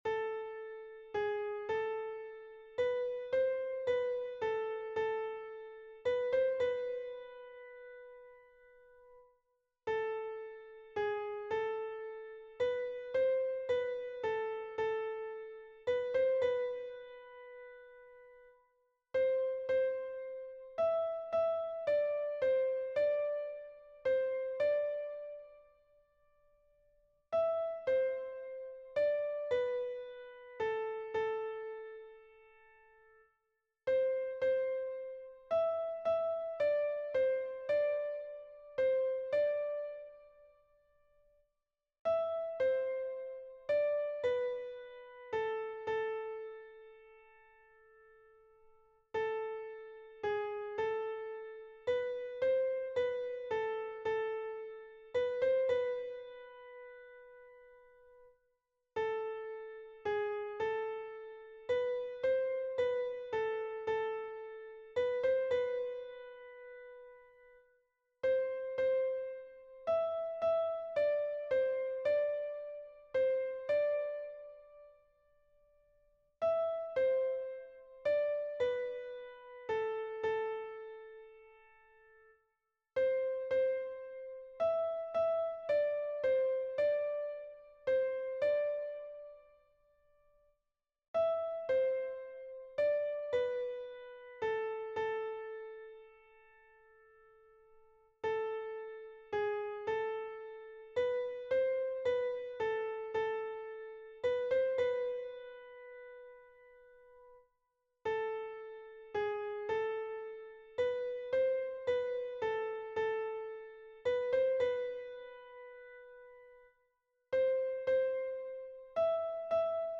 - Oeuvre pour choeur à 3 voix mixtes